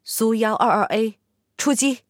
SU-122A出击语音.OGG